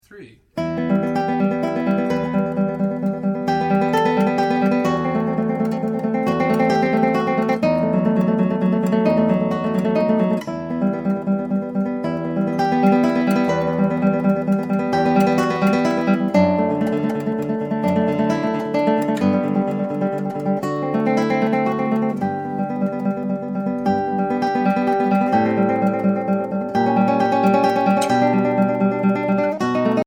Voicing: Guitar Collection